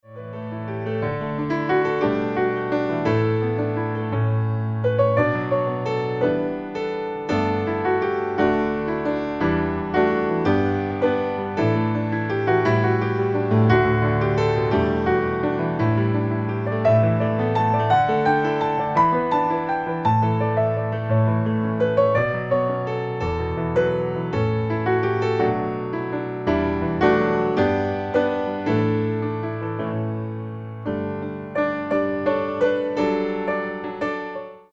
a series of instrumental recordings